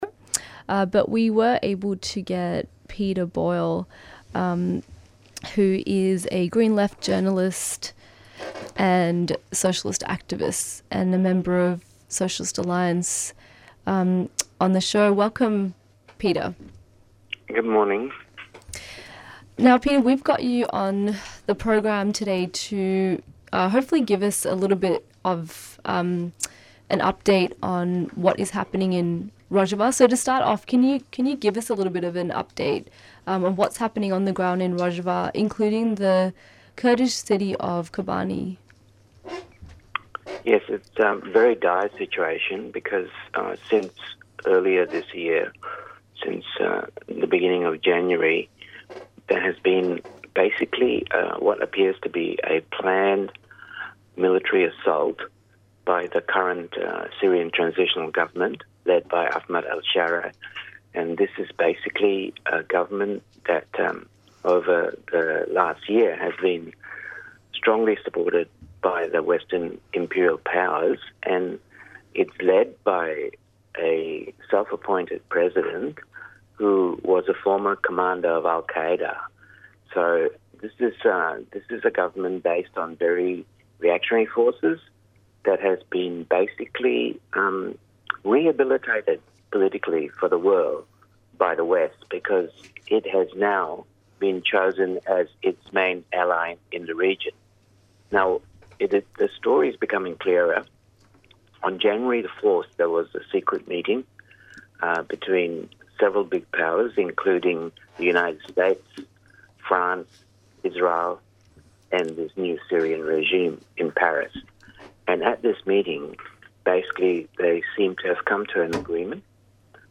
Presenters discuss the latest news from the pages of Green Left and other alternative media.
Interviews and Discussion